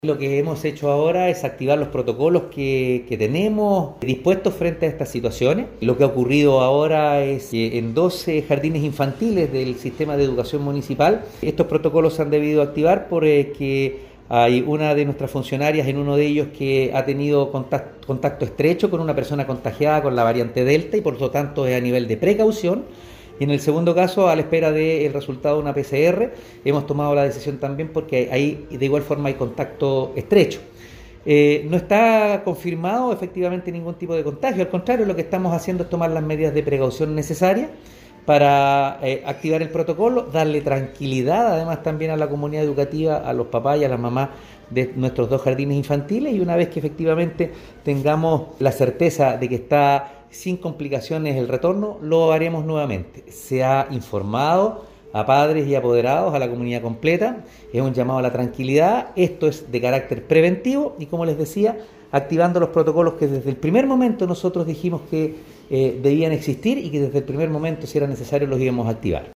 En este sentido, el alcalde Oscar Calderón Sánchez indicó que ha sido importante el rápido actuar de los equipos directivos de la RedQ y los jardines para resguardar ante la mínima posibilidad la salud de nuestros niños y niñas, como también de nuestros funcionarios.
Alcalde-Oscar-Calderon-Sanchez-2.mp3